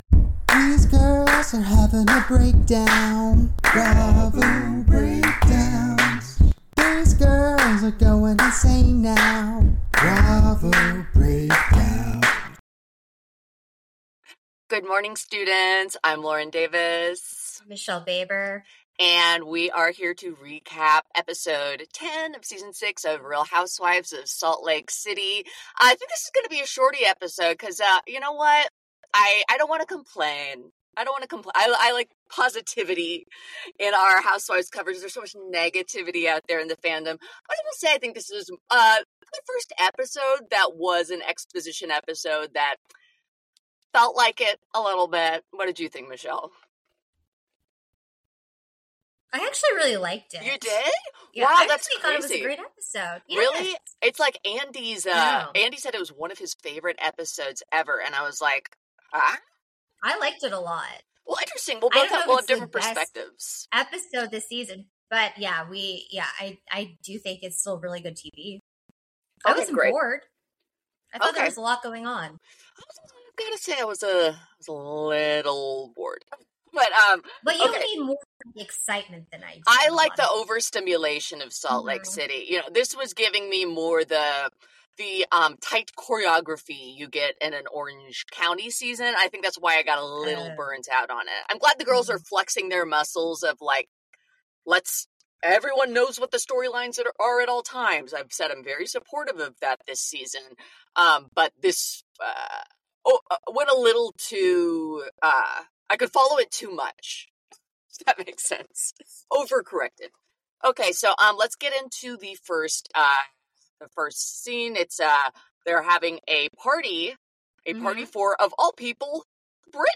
We're two former stand-up comedians and joke writers with a long list of comedic accomplishments and mental illness diagnoses- and now, all of our time, obsession, passion, talent, wit, and neuroses are dedicated entirely to recapping reality shows.